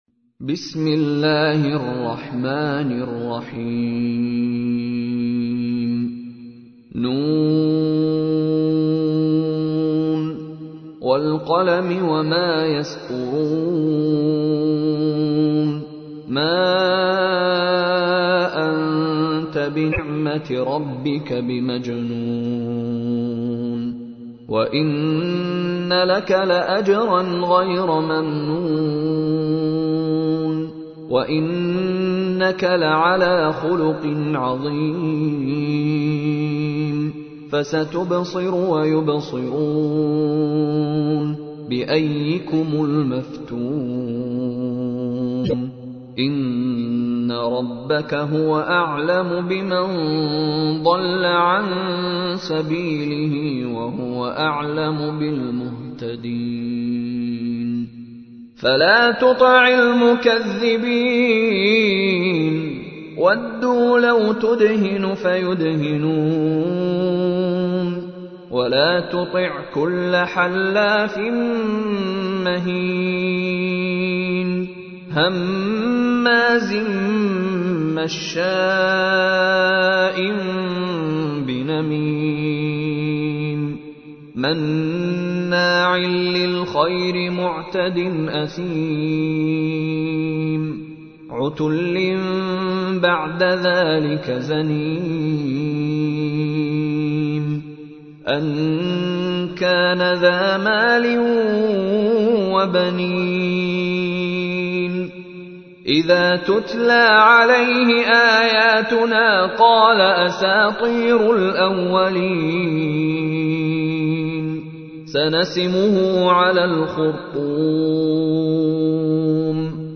تحميل : 68. سورة القلم / القارئ مشاري راشد العفاسي / القرآن الكريم / موقع يا حسين